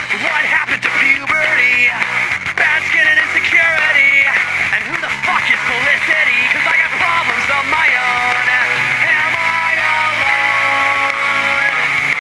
rock band